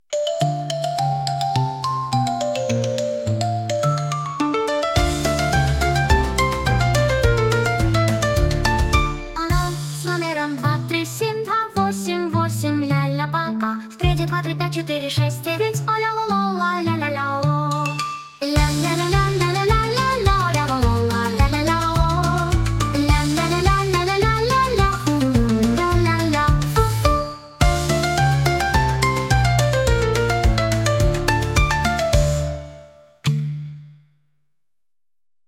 Забавная песенка от забавной баки, что может быть лучше..